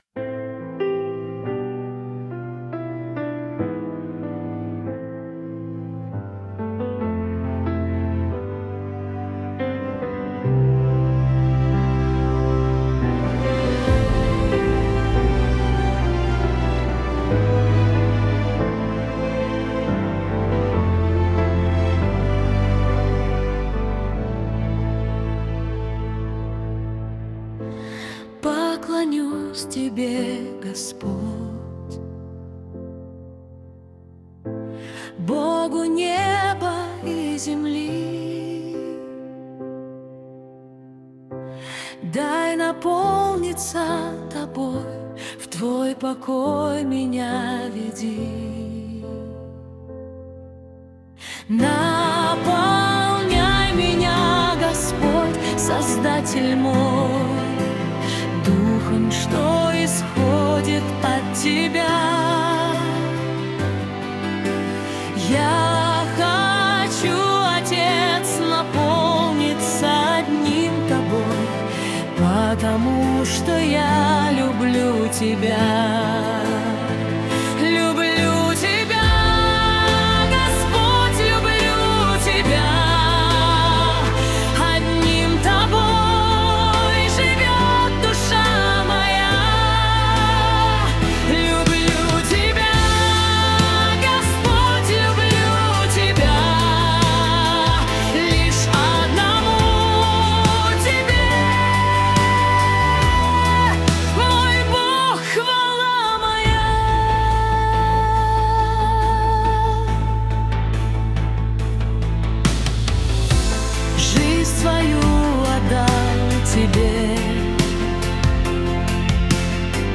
песня ai
Аранжеровка и вокал ИИ